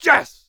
EXCLAMATION_Male_B_Yes_mono.wav